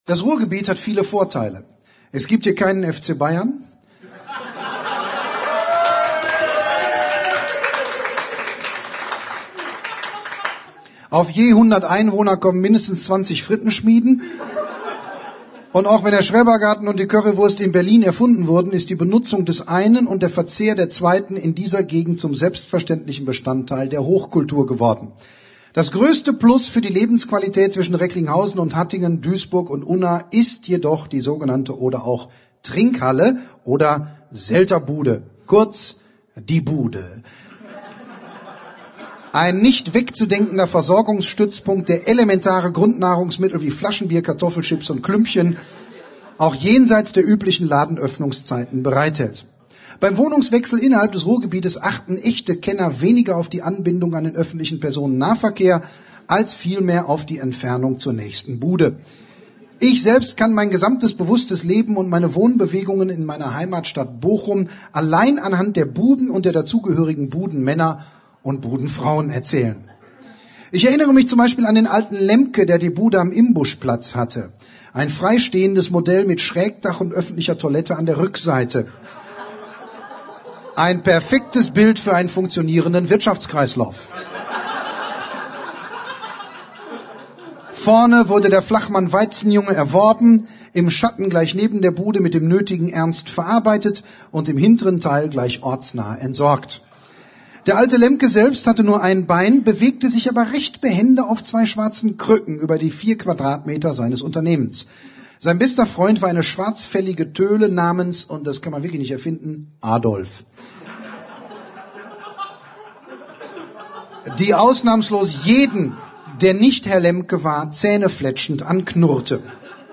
Frank Goosen (Sprecher)
2007 | 7. Auflage, Gekürzte Ausgabe
In Frank Goosens neuem Live-Programm ist die Gegend zwischen Duisburg und Unna, zwischen Recklinghausen und Hattingen Thema - das Ruhrgebiet.